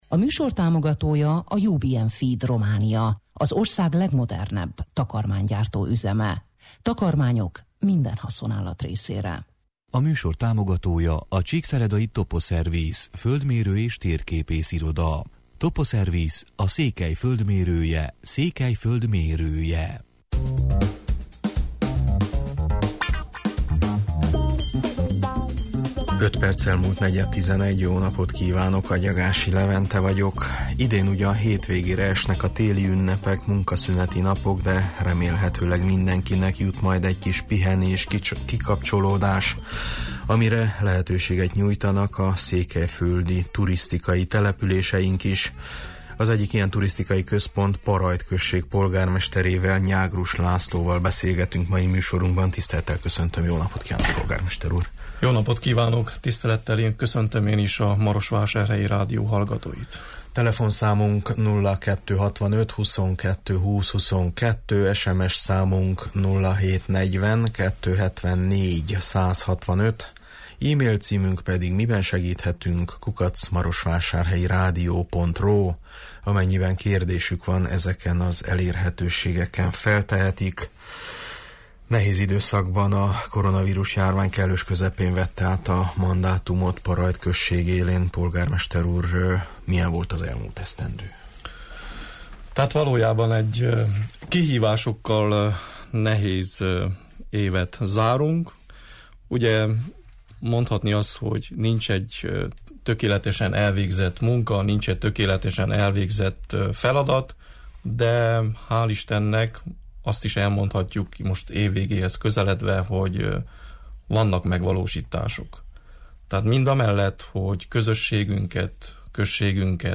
A turisták nem kerülték el idén nyáron sem Parajdot, az önkormányzat és a turisztikai szolgáltatók pedig felkészültek a turisták fogadására a téli ünnepek idején is. Parajd polgármesterével, Nyágrus Lászlóval beszégettünk turizmusról, a községben zajló beruházásokról, a Bucsin-tető villamosításáról: